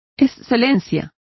Complete with pronunciation of the translation of excellence.